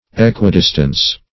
Equidistance \E`qui*dis"tance\, n. Equal distance.